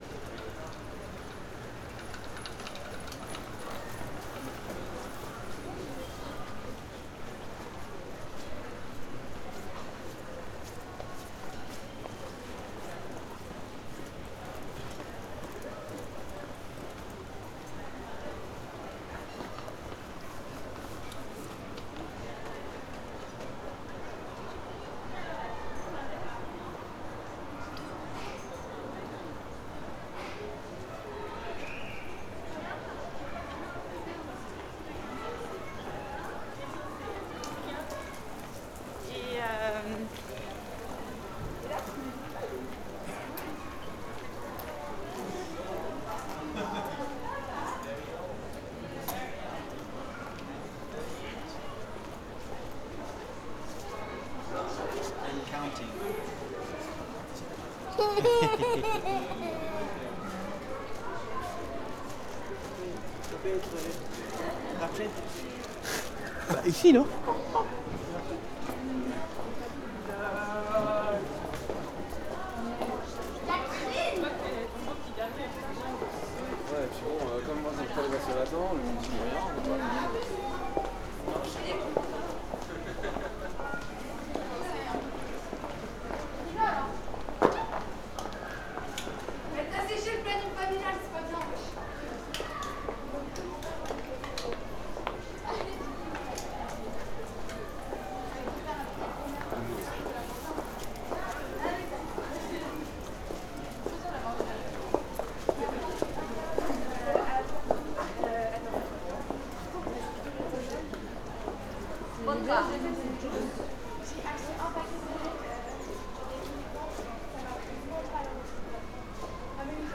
Paris_street_large1.L.wav